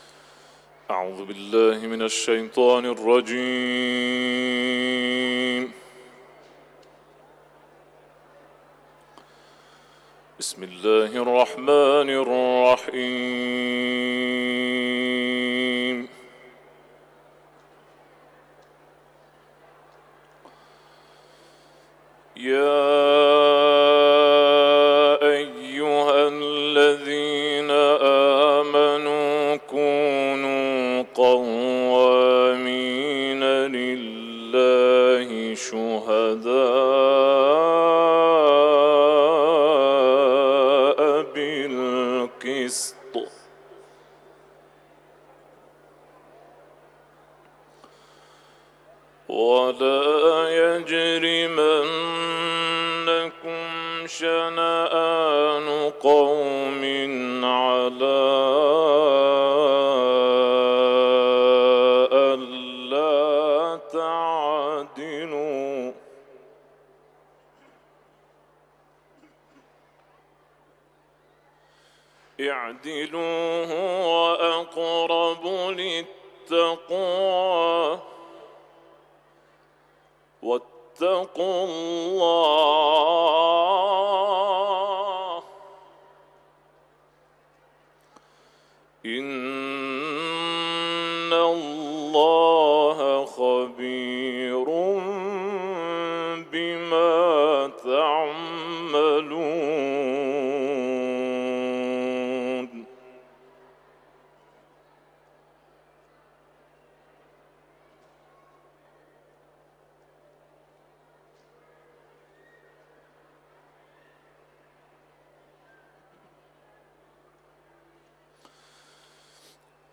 تلاوت ، سوره نساء